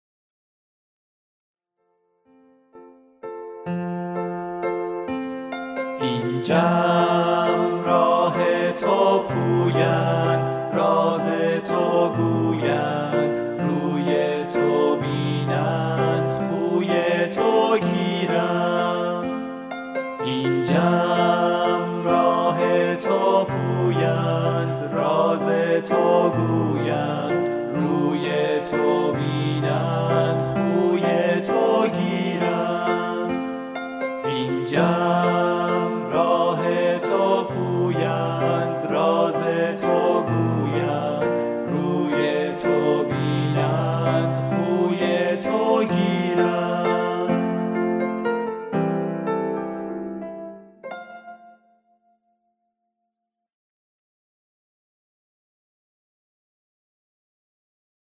دعا و نیایش با موسیقی